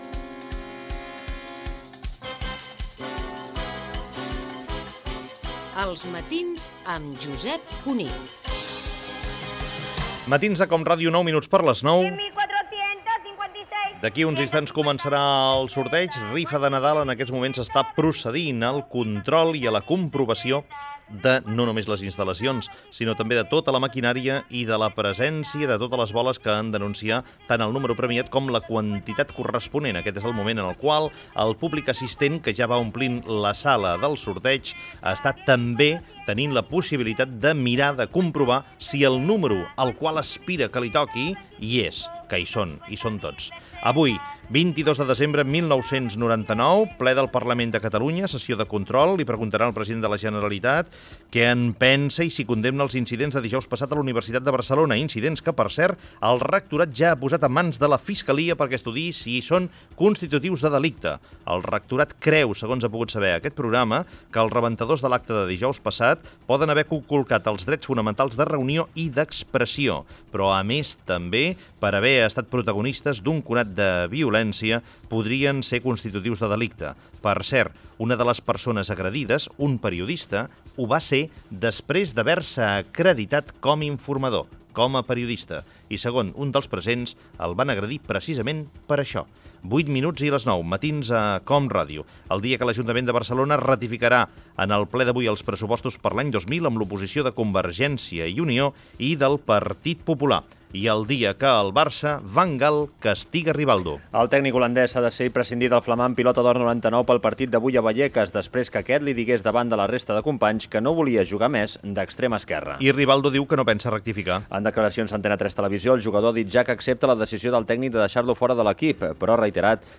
Info-entreteniment
Fragment extret de l'arxiu sonor de COM Ràdio.